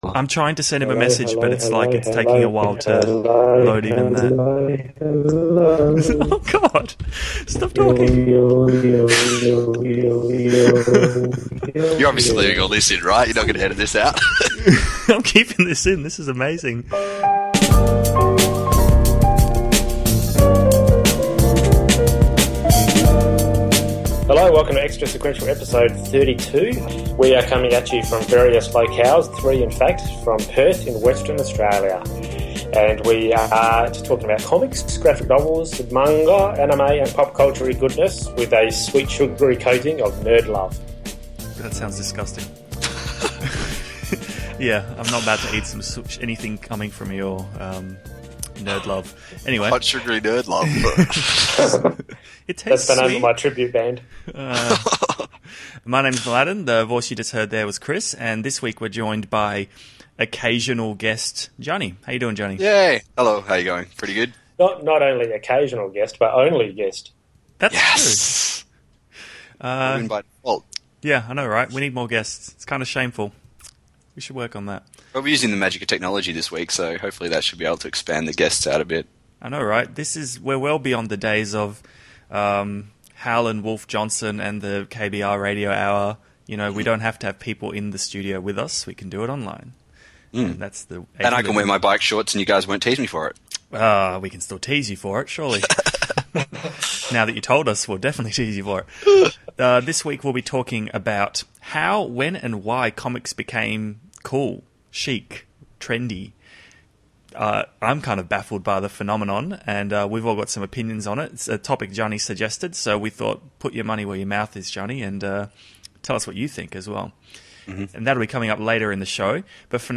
Also, the correct pronunciation of Ben Affleck, replacing beer with soda pop and the differing meanings of the word jocks. Plus, as an added bonus after the end song, hear some Skype shenanigans.
THEME-COMICS ARE NOW COOL. DISCUSS. Which we do, despite me dropping off Skype occasionally.